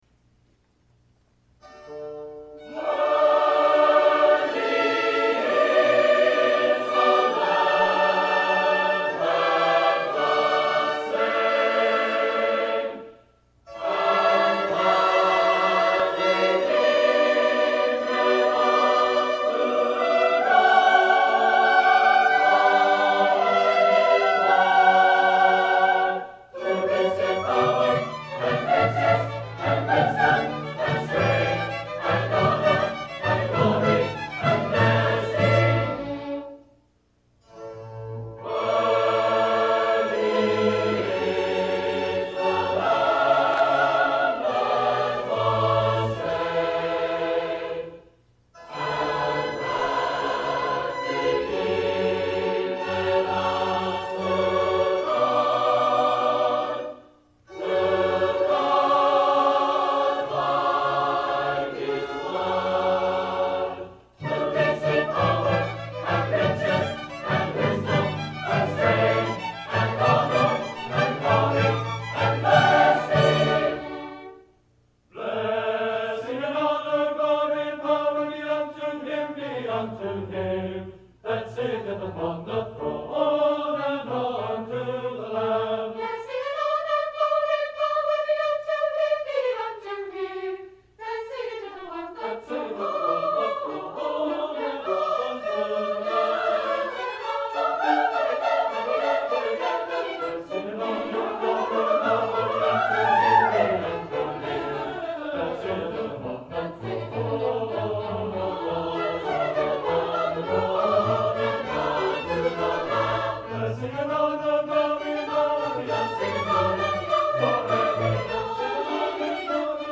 Bethany Nazarene College Choral Society & Orchestra present Handel's Messiah. Performed and recorded in Herrick Auditorium on the campus of Bethany Nazarene College on 07 December 1981 at 8:00PM.